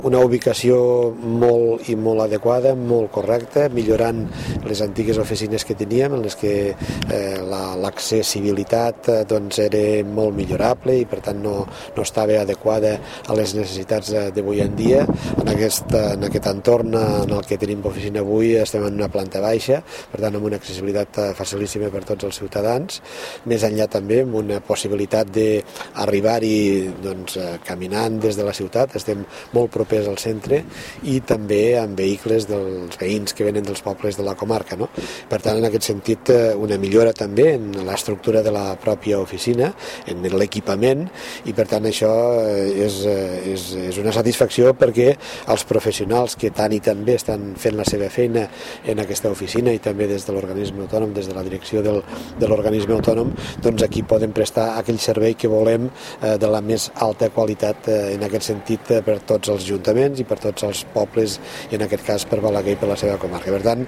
El president de la Diputacio de Lleida, Joan Reñé, destaca la gran millora que s’ha obtingut amb el canvi, tant a nivell d’estructura com de facilitat d’accés per als ciutadans que han d’anar a fer diferents gestions.